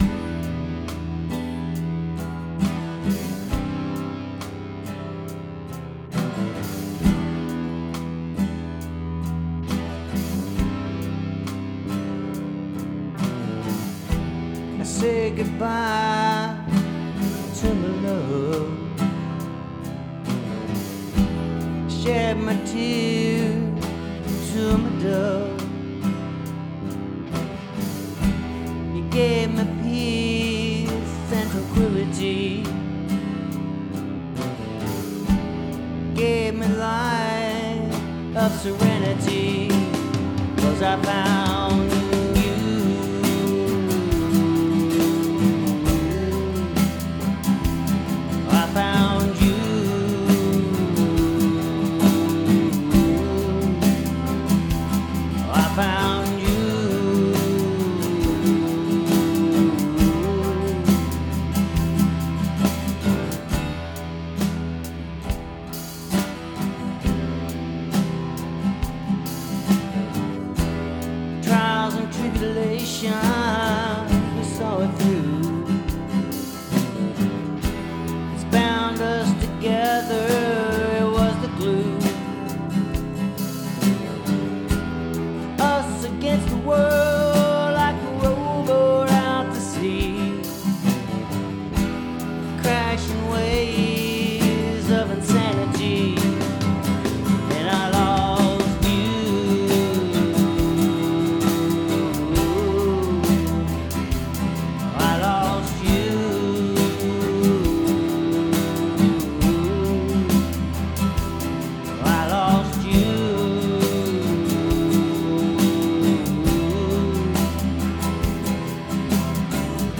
OK, this is about 80% there.
This is my first mix with monitors and some mild room treatment. EZdrummer on the drums (not sure I like that program yet), this was the $29 special (something like that) Two acoustic channels/two mics, single take, panned mild left and right Bass up the middle Two tracks electric, two takes, hard panned right and left (different to add depth) Vocal, dry main, slight reverb on send channel.